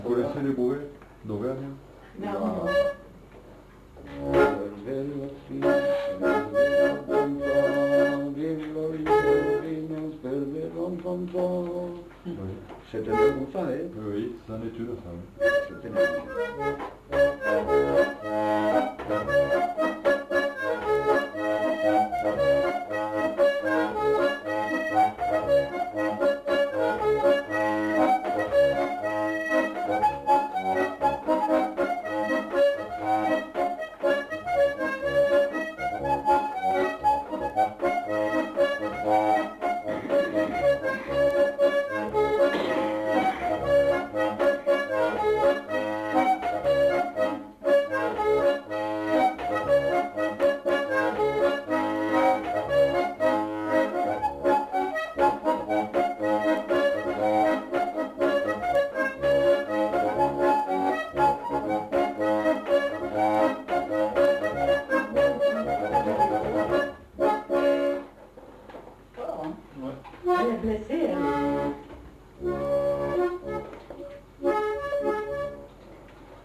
Aire culturelle : Bigorre
Lieu : Aulon
Genre : morceau instrumental
Instrument de musique : accordéon diatonique
Danse : bourrée